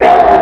Krumple's defeat sound with real pinch.